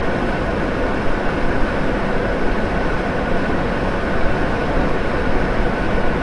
家用 " 风扇快速
描述：台式风扇速度快
标签： 空气 风扇 风扇 空调 空调 ventila和灰 鼓风机 空调
声道立体声